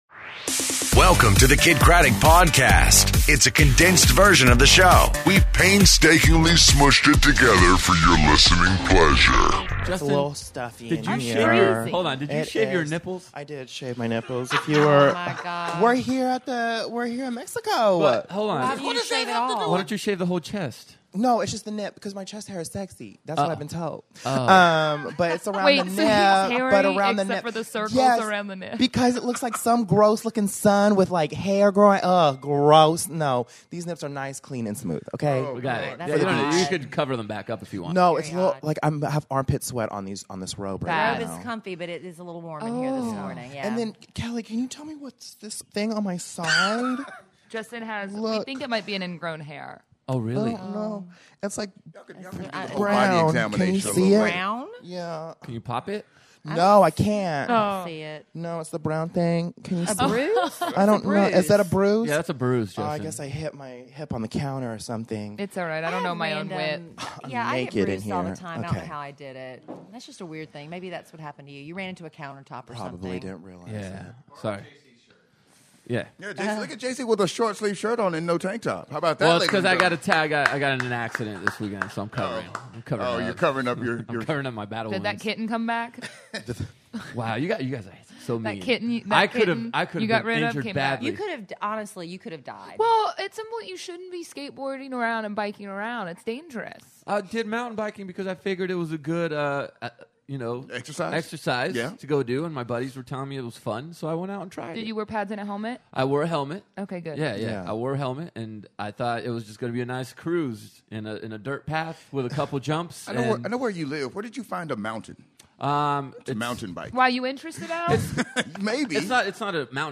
Live from Mexico! Hear all about our Family Vacation!